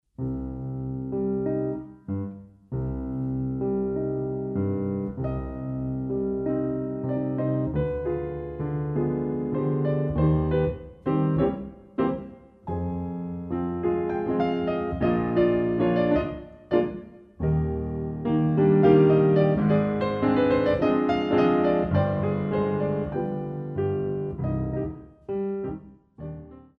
Young dancers Ballet Class
The CD is beautifully recorded on a Steinway piano.